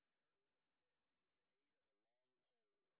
sp09_street_snr0.wav